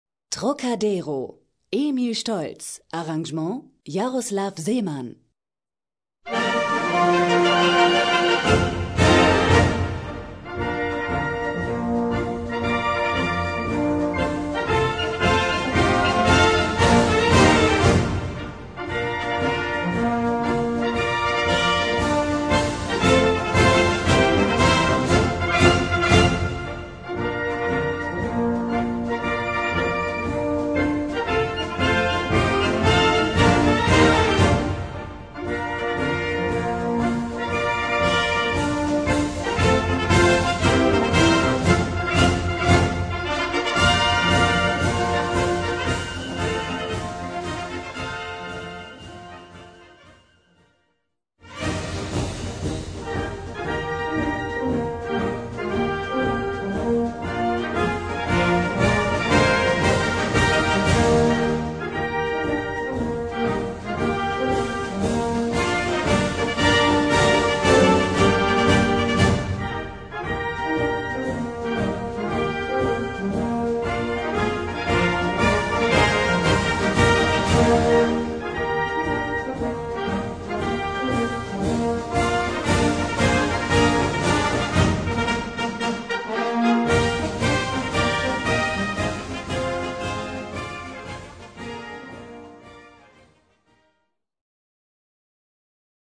Gattung: Marsch
Besetzung: Blasorchester
ist ein schmissiger und gut gelaunter Straßenmarsch